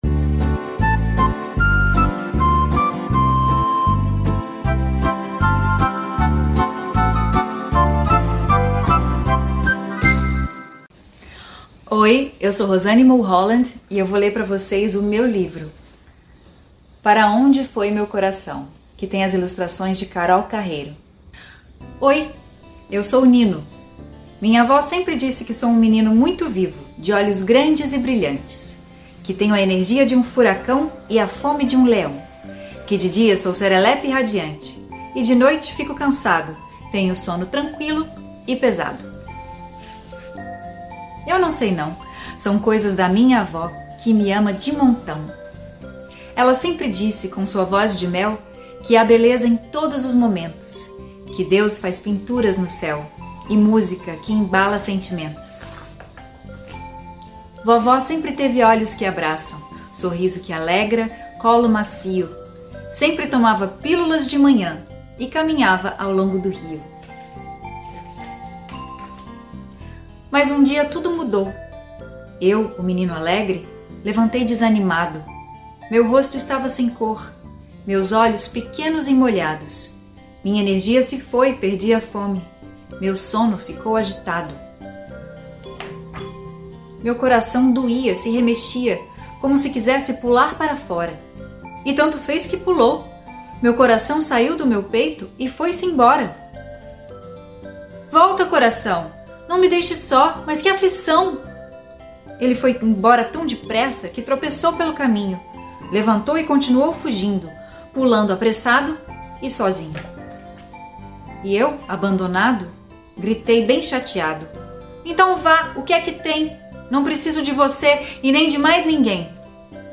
Autora: Rosanne Mulholland Narrado por Rosanne Mulholland Voltar à página de áudios
6-rosanne_brasilia_historia-infantil_para-onde-foi-meu-coracao.mp3